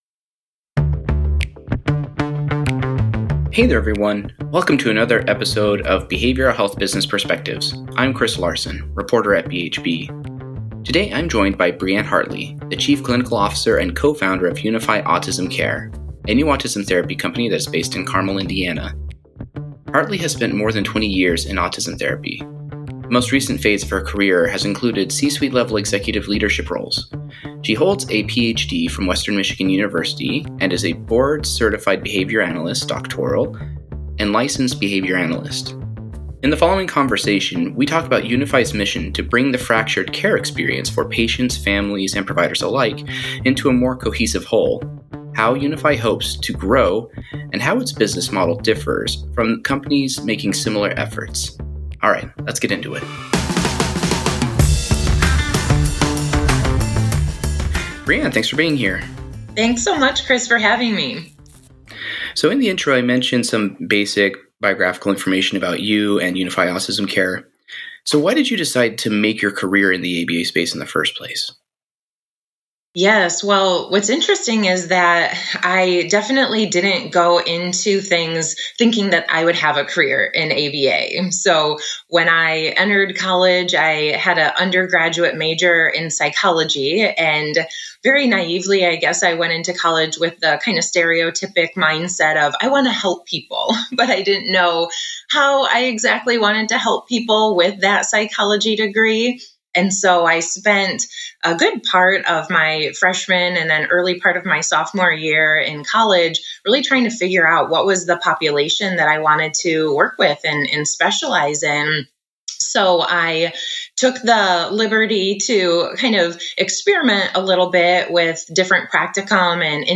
for a conversation about some of the top issues facing the industry today. They discuss how UNIFI plans to bring the many health care providers in the orbit of families of children with autism together into a team with applied behavior analysis (ABA) at its core.